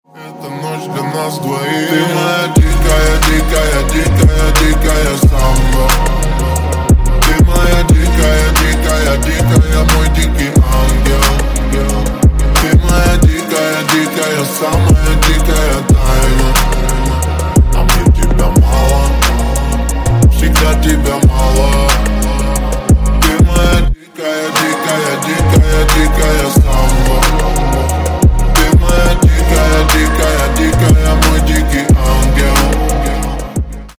Громкие Рингтоны С Басами
Поп Рингтоны